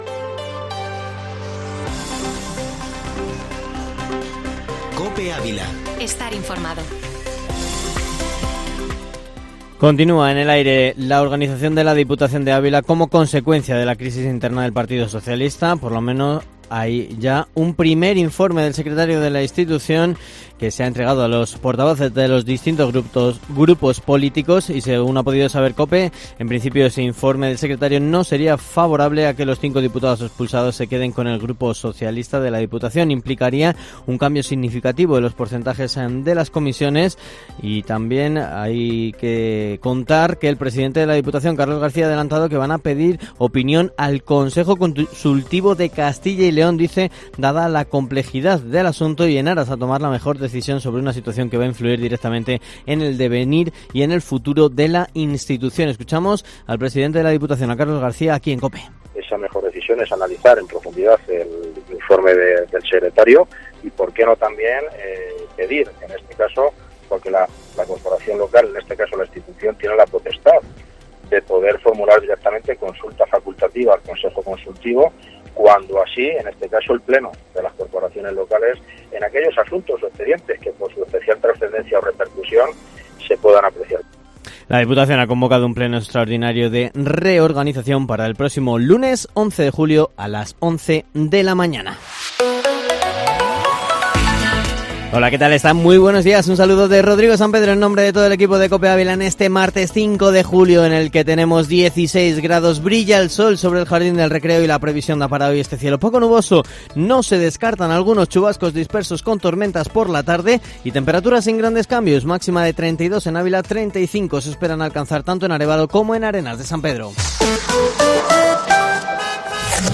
Ávila